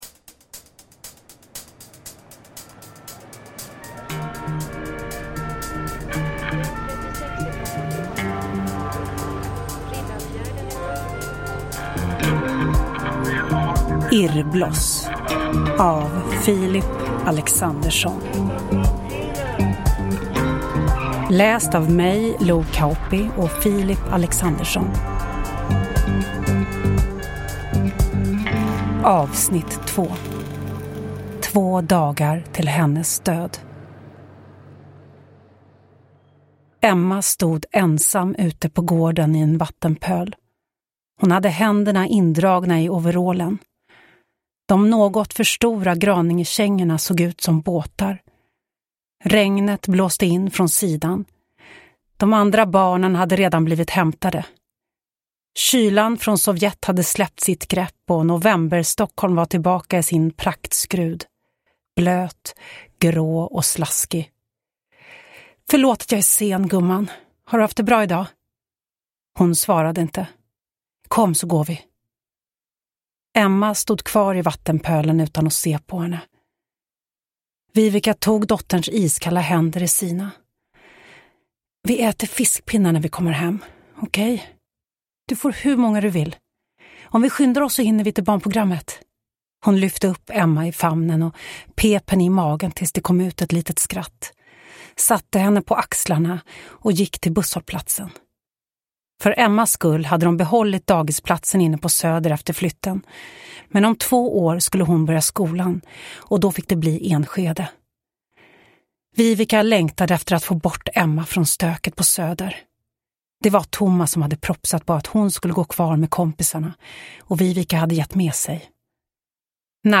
Hidden S1A2 Irrbloss : Två dagar till hennes död – Ljudbok – Laddas ner